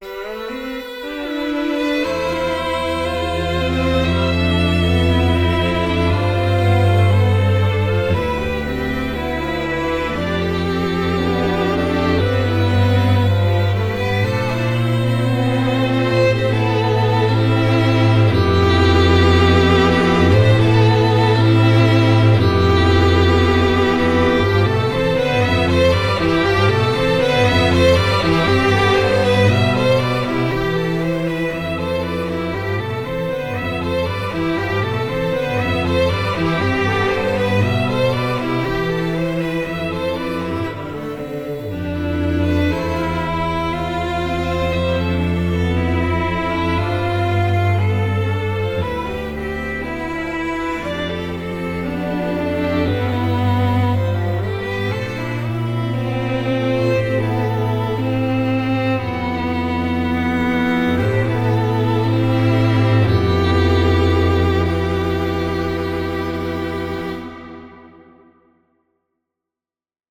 For Strings
Wandering-Alone-Strings.mp3